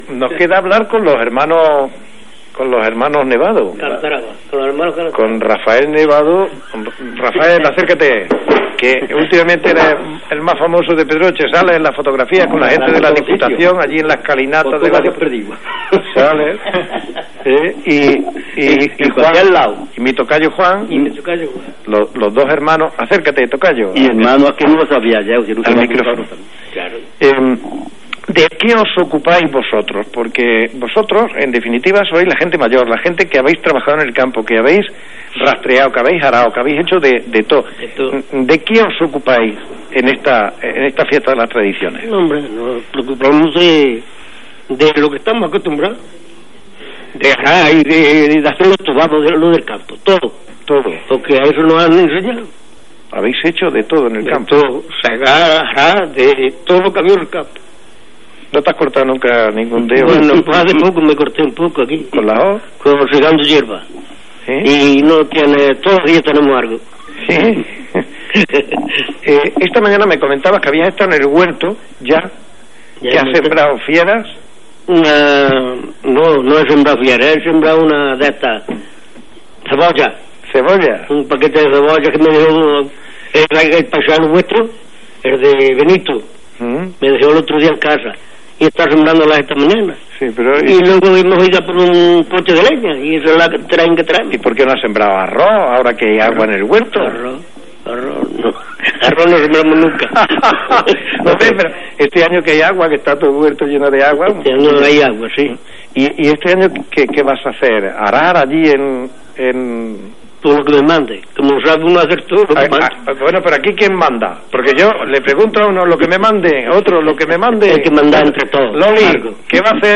Entrevistas realizadas por RADIO LUNA SER, el 13 de abril, en un programa especial realizado desde Pedroche.